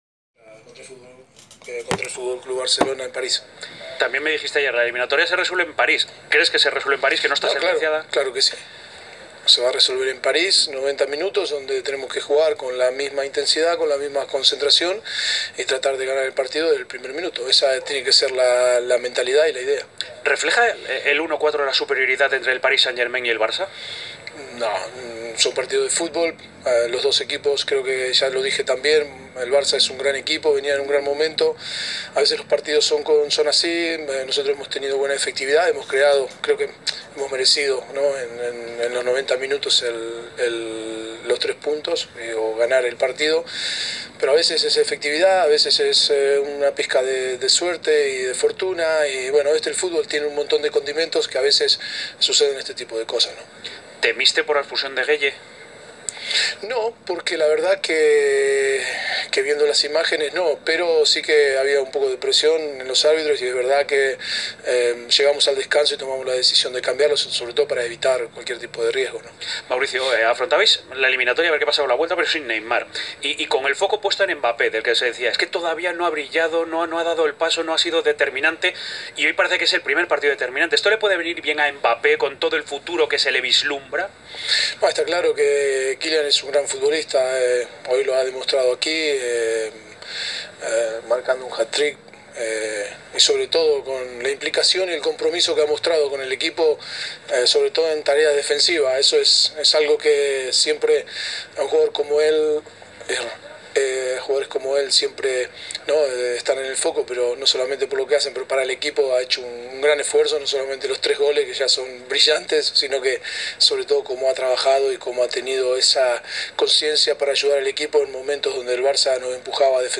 En rueda de prensa el estratega del Barcelona, Ronald Koeman, reconoció su derrota y espera mejorar.
Audio Mauricio Pochettino, técnico del PSG